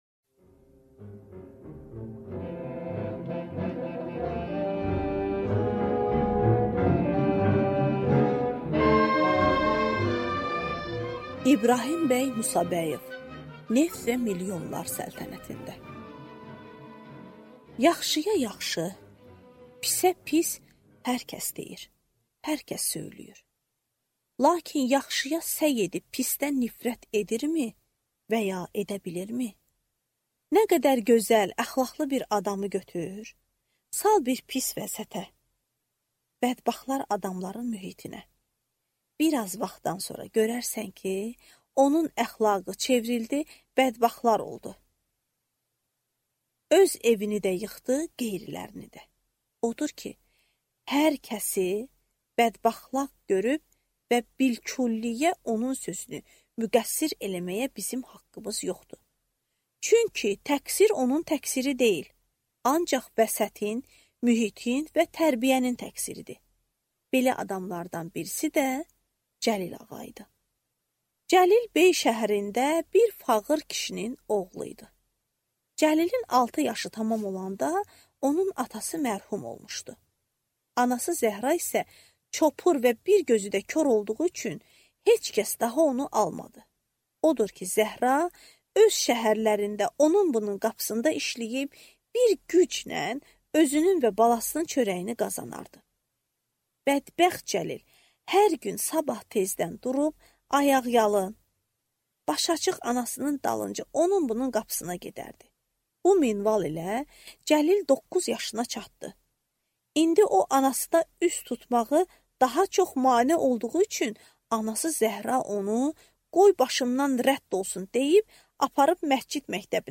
Аудиокнига Neft və milyonlar səltənətində | Библиотека аудиокниг
Прослушать и бесплатно скачать фрагмент аудиокниги